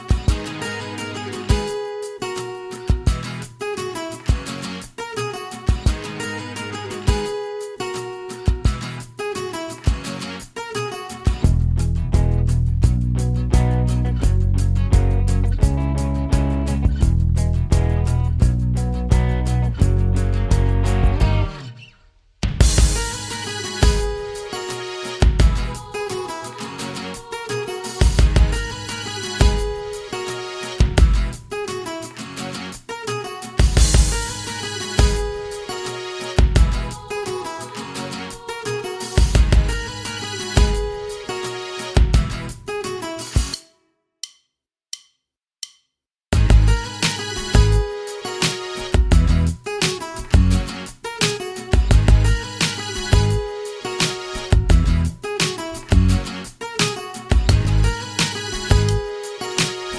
Minus Main Guitar For Guitarists 3:50 Buy £1.50